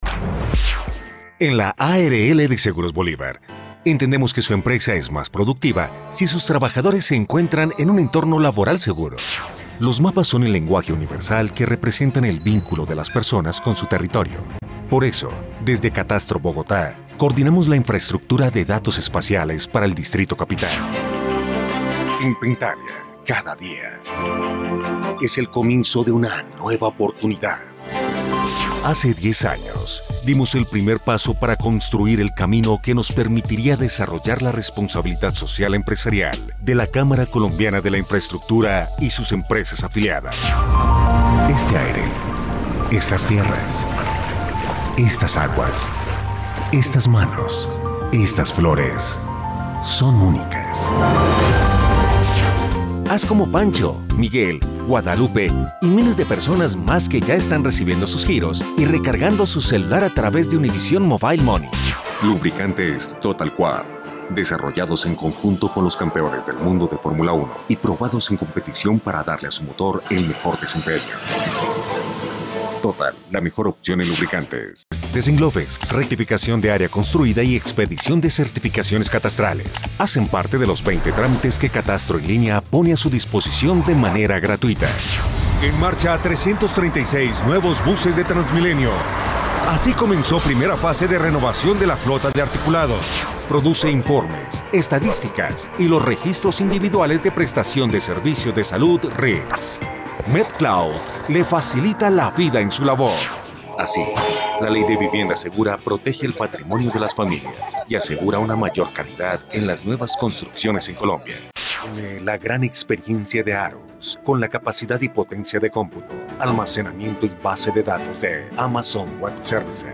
Micrófono Shure Pg Alta Pga27 Condensador Cardioide M-Audio M-Track 2-Channel USB Audio Interface Adobe audition 2020
kolumbianisch
Sprechprobe: Sonstiges (Muttersprache):